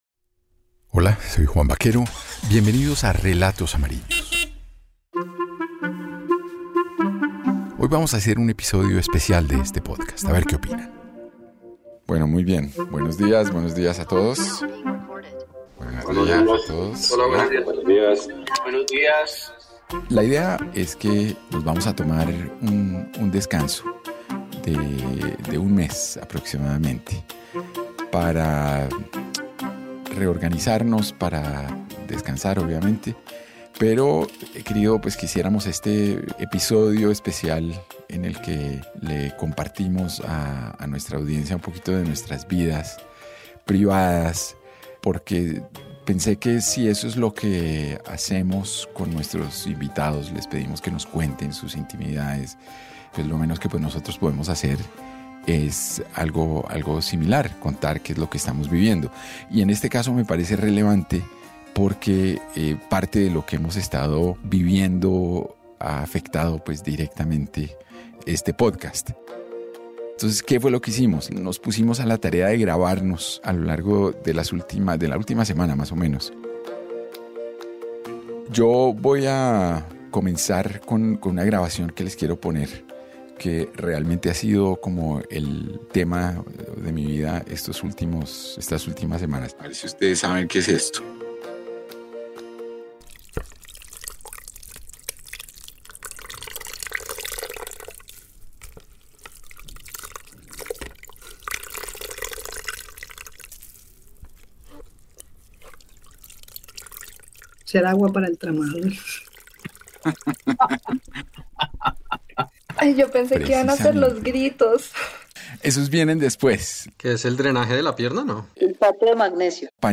Detrás de Relatos Amarillos, también hay historias, personas y emociones. En este capítulo, escucharemos a viva voz al equipo que ha dado vida a tan enriquecedor proyecto para mostrarnos su faceta más humana de forma empática, cercana y casi íntima.